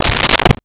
Car Door Closed 01
Car Door Closed 01.wav